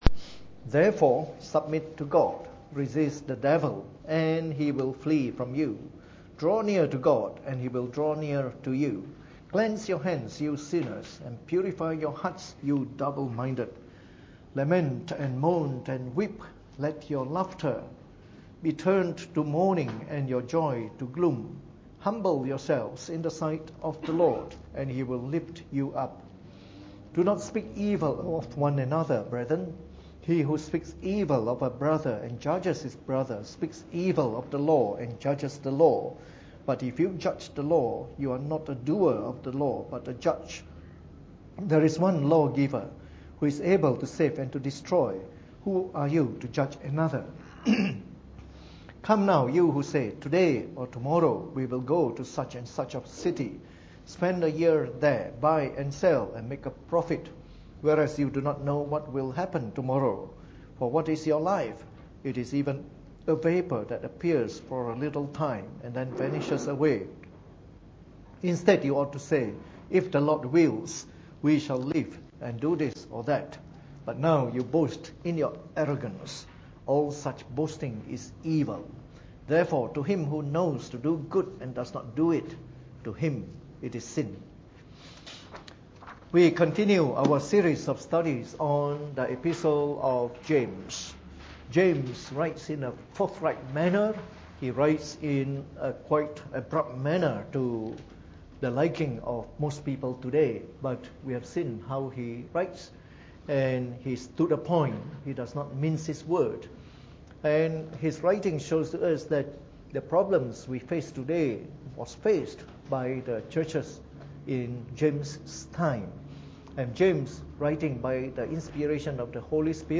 Preached on the 6th of January 2016 during the Bible Study, from our series on the Epistle of James.